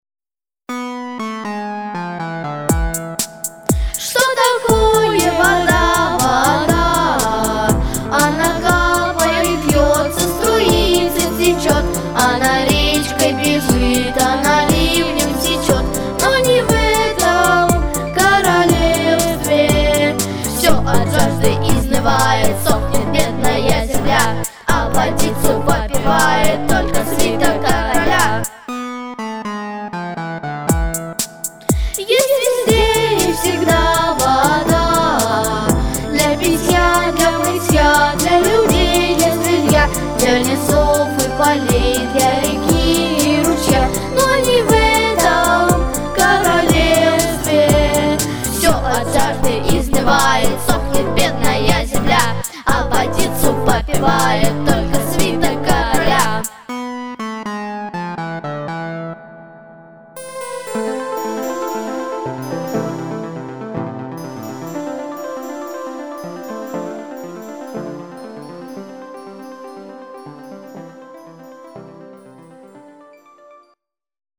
II Театральный Фестиваль начальной школы